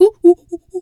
pgs/Assets/Audio/Animal_Impersonations/monkey_2_chatter_13.wav at master
monkey_2_chatter_13.wav